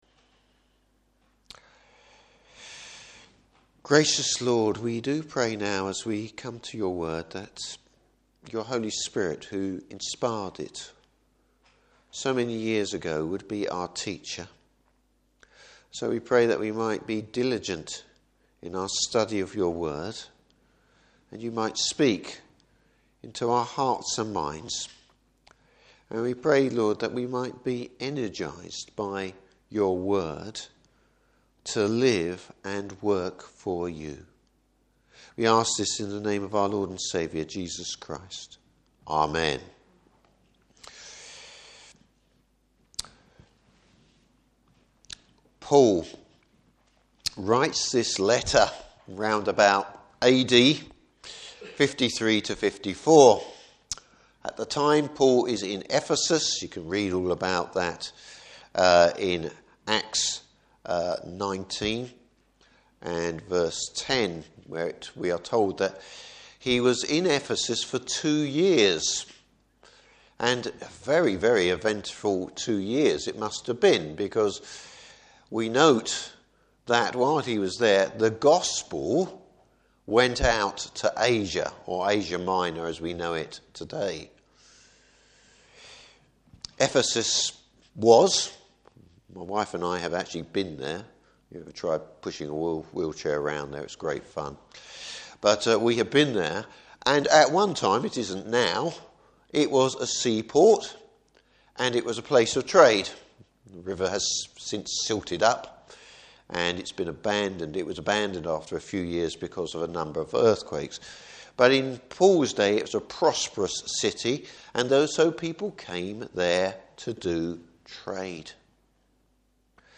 Service Type: Evening Service Paul urges the Corinthian Church to focus on the work of Christ.